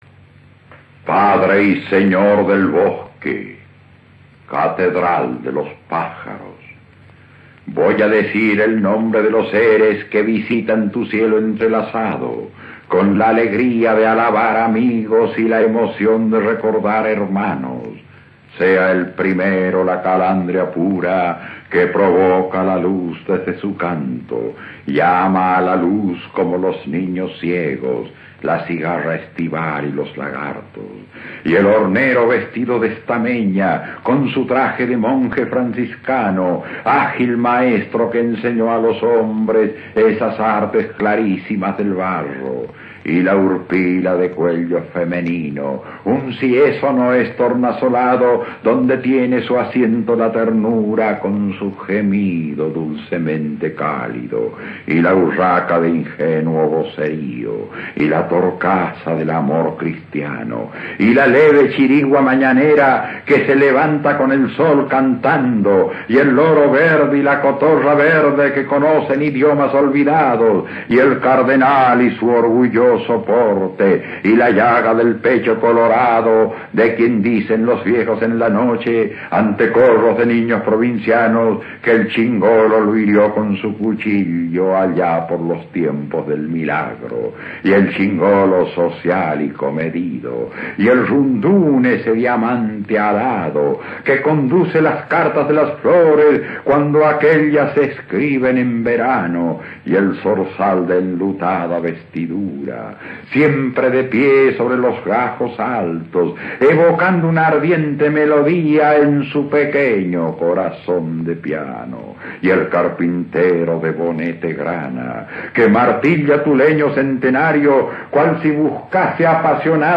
47. Antonio Esteban Aguero recita su "Cantata del abuelo algarrobo" (un fragmento).